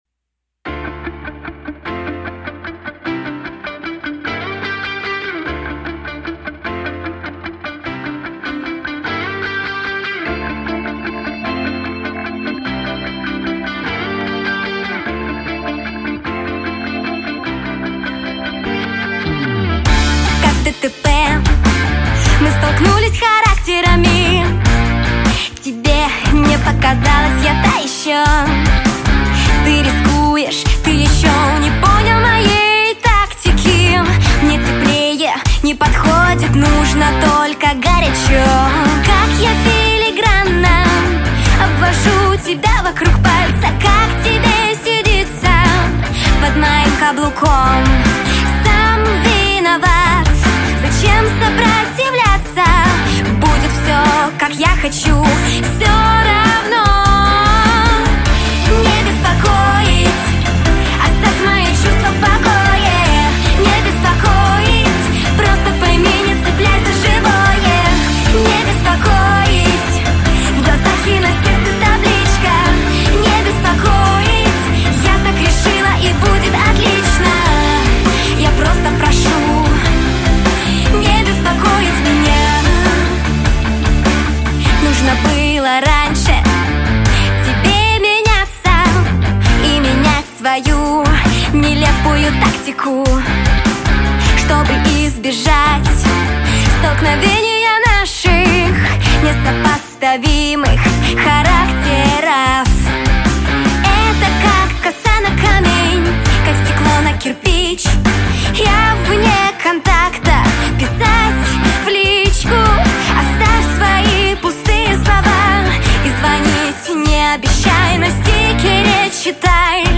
Сопрано
Спеціальність: эстрадно-джазовый вокал